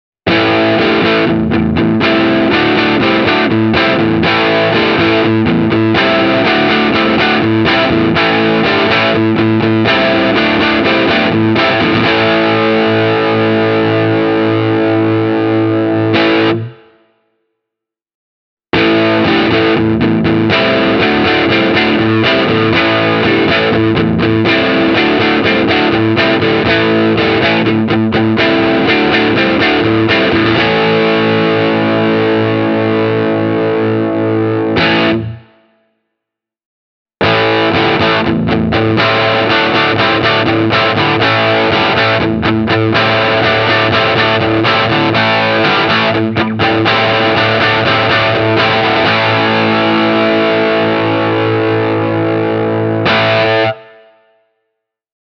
This Tokai really loves overdriven sounds, with the excellent-sounding humbuckers dishing out the goods aplenty for all your Blues-, Rock- and Metal-needs.